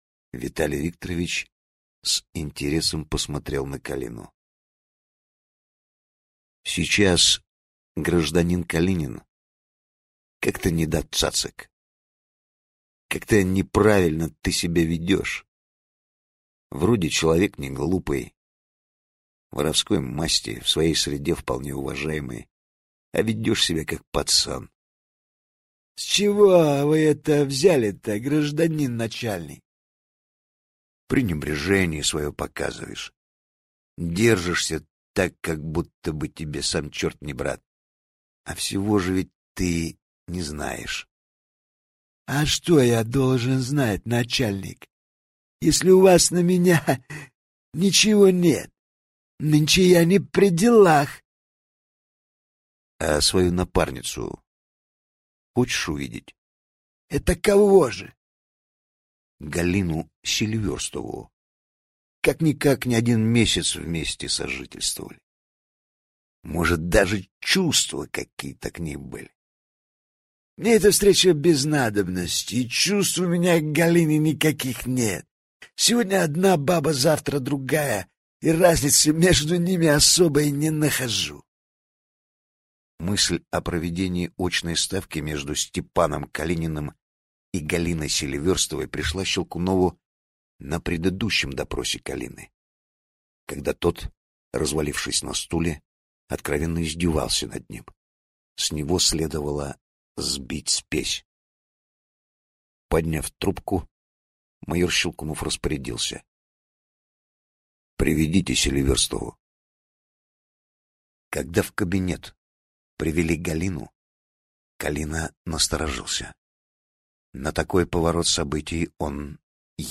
Аудиокнига Тайный узел | Библиотека аудиокниг